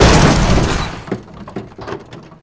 brute_impact_hev1.wav